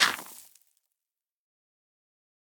brushing_gravel_complete1.ogg